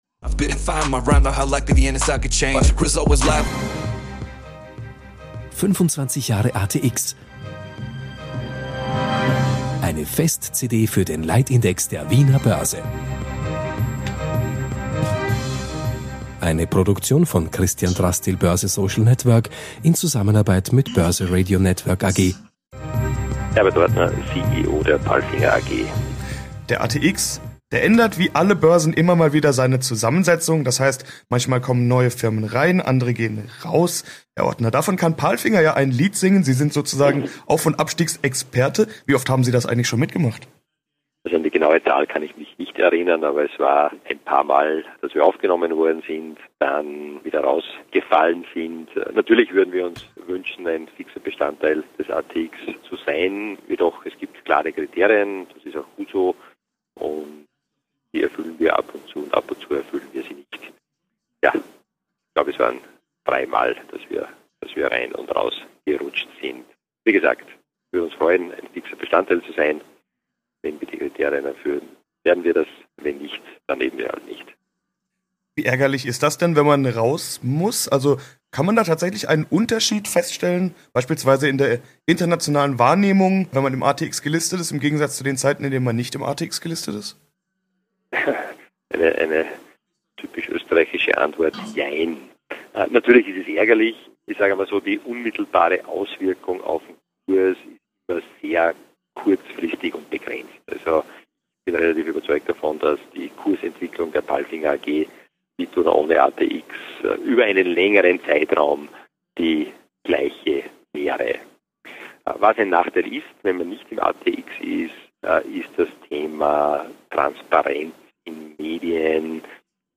10 Years After gibt es nun täglich ein Kapitel aus dem Hörbuch.